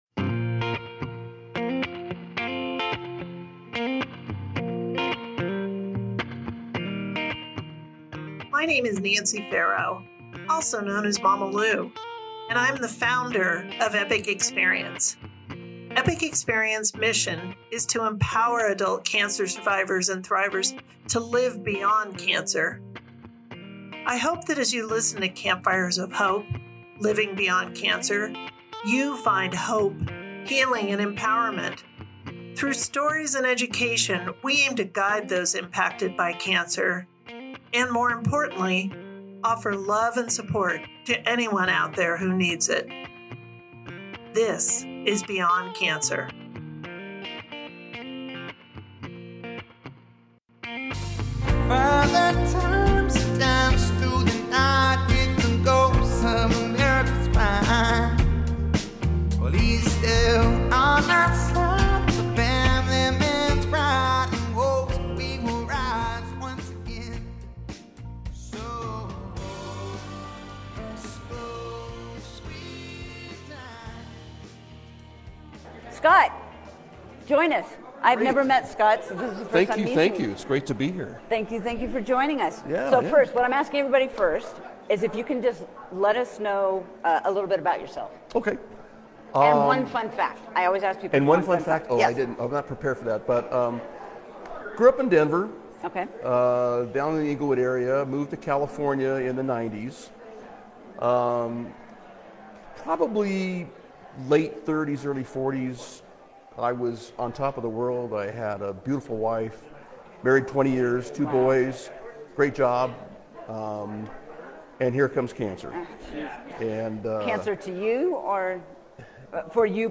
Live from the Hearts & Hope Gala (Part 3)